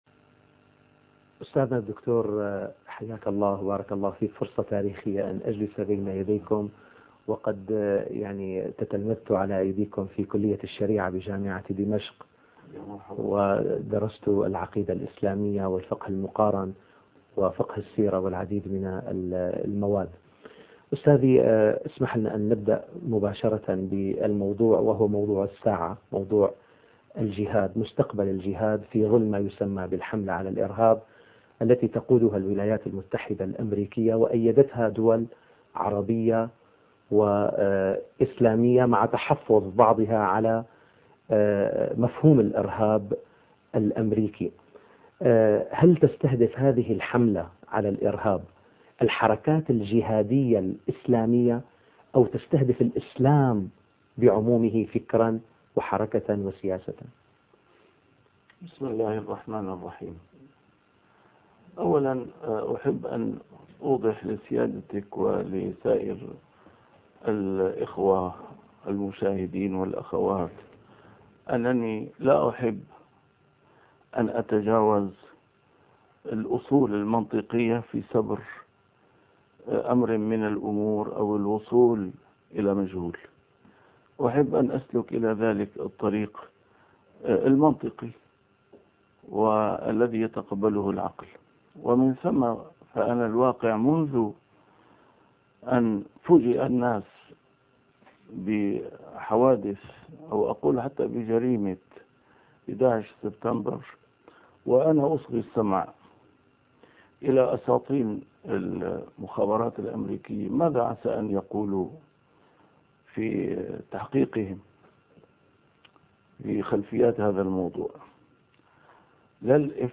محاضرات متفرقة في مناسبات مختلفة - A MARTYR SCHOLAR: IMAM MUHAMMAD SAEED RAMADAN AL-BOUTI - الدروس العلمية - مستقبل الجهاد في ظل الحملة على الإرهاب